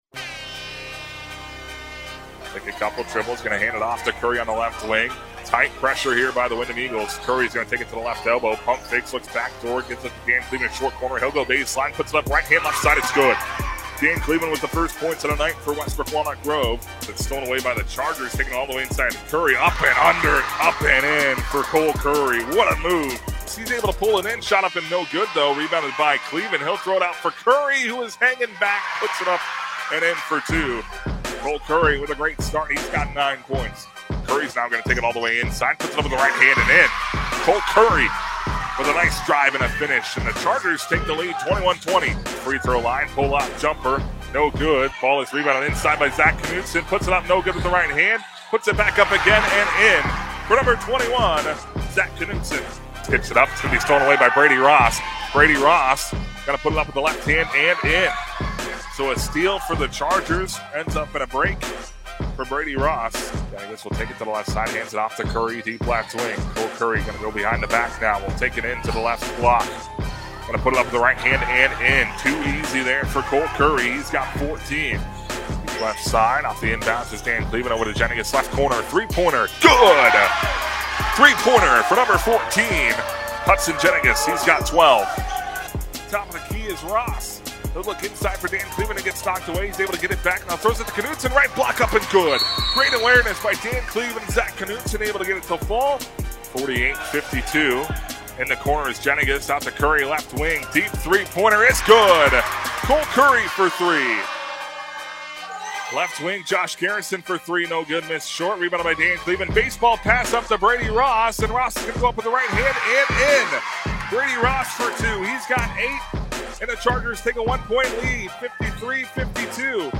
WWG Highlights with music 12-6-22
WWG-Highlights-with-music-12-6-22.mp3